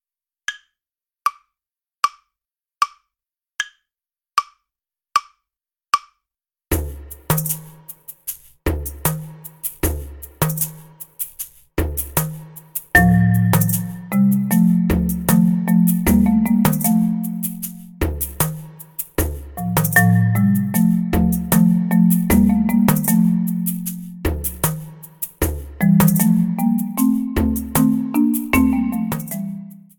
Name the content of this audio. Besetzung: Schlagzeug Drumset Playalongs